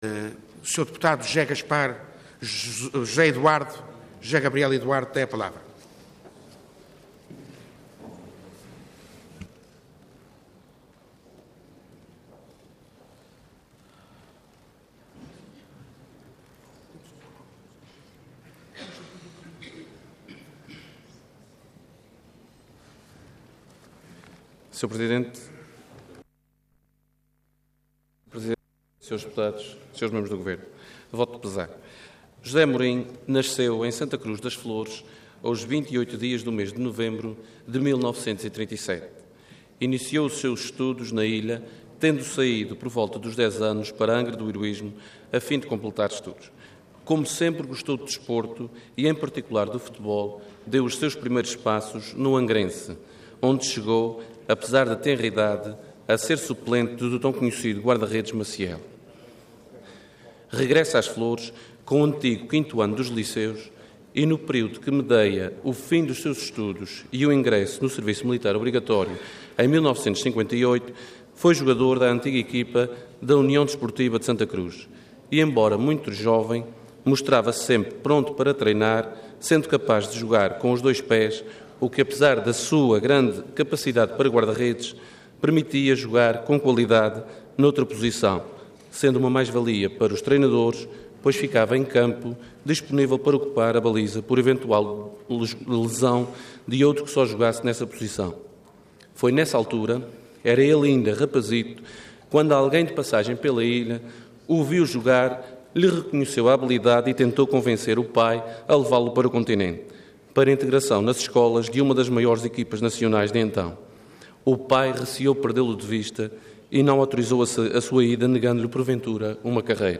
Intervenção